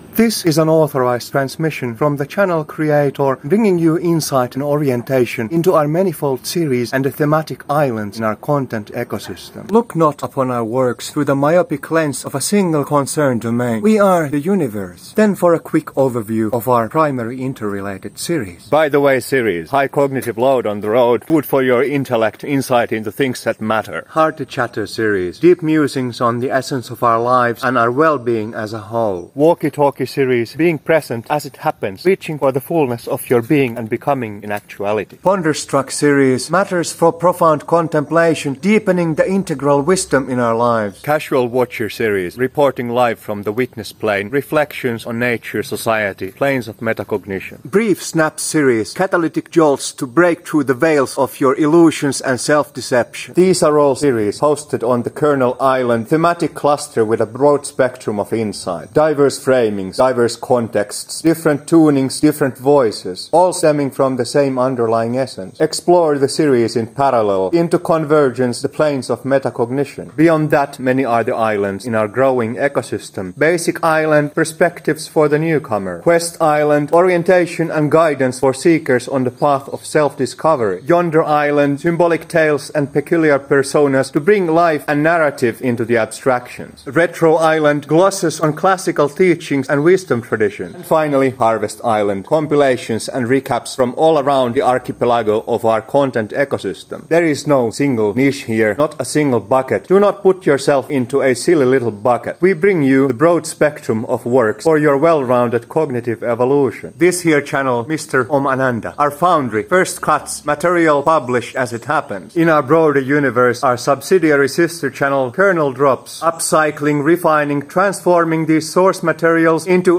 Direct message from the Channel Creator.